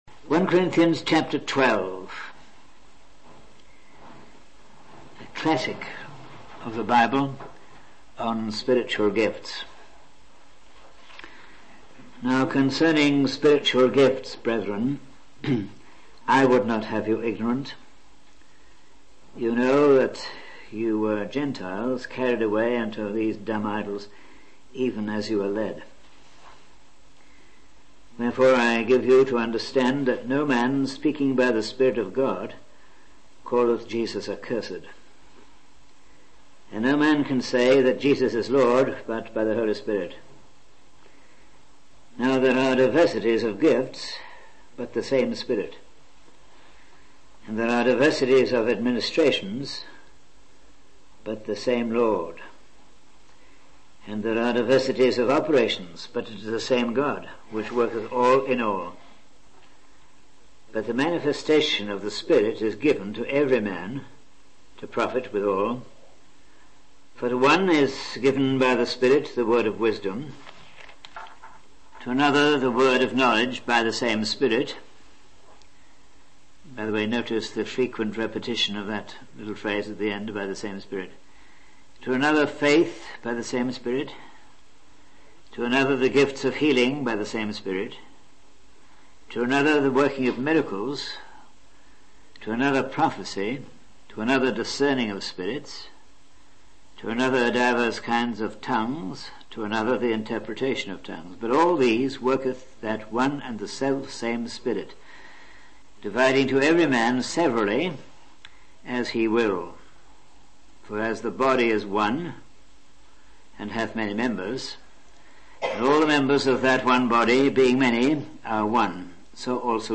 In this sermon, the speaker emphasizes the importance of being still and silent in our hearts to hear the will of God. He highlights the gifts of the Spirit mentioned in 1 Corinthians 12:4-7, including the baptism of the Spirit and the various roles in the church such as apostles, prophets, and teachers.